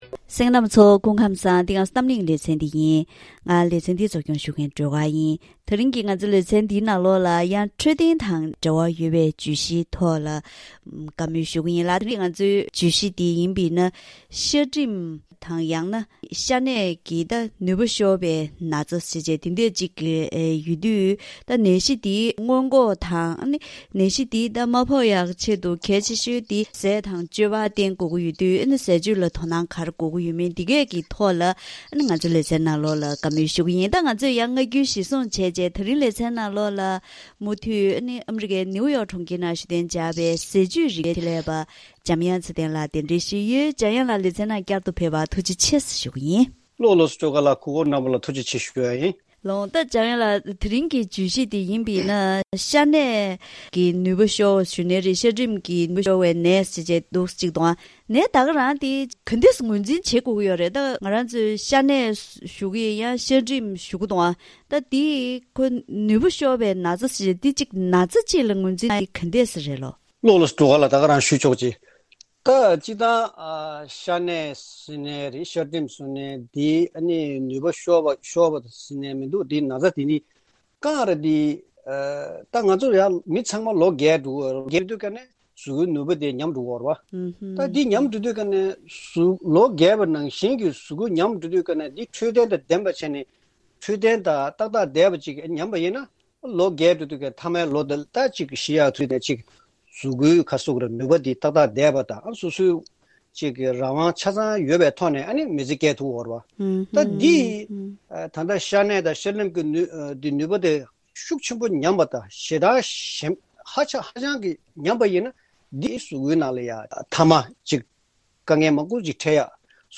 ད་རིང་གི་གཏམ་གླེང་ཞལ་པར་ལེ་ཚན་ནང་ཤ་གནད་ཀྱི་ནུས་པ་ཤོར་བ་འདི་ནད་ཅིག་ལ་ངོས་འཛིན་བྱེད་ཀྱི་ཡོད་པ་དང་། ཤ་གནད་ཀྱི་ནུས་པ་ཤོར་ན་གཟུགས་གཞིའི་འཕྲོད་བསྟེན་ལ་གནོད་འཚེ་གང་ཡོད་པ་དང་། དུས་རྒྱུན་འཚོ་བའི་གོམས་གཤིས་ངན་པའི་ཁྲོད་ནས་ནད་གཞི་འདི་ཕོག་གི་ཡོད་པས། ཉིན་རེའི་འཚོ་བའི་ནང་ཟས་སྤྱོད་ལ་བསྟེན་ཚུལ་དང་སྔོན་འགོག་ཡོང་ཐབས་སྐོར་ལ་ཟས་བཅུད་རིག་པའི་ཆེད་ལས་པ་དང་ལྷན་དུ་བཀའ་མོལ་ཞུས་པ་ཞིག་གསན་རོགས་གནང་།